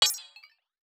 Game Notification Sound Hollow.wav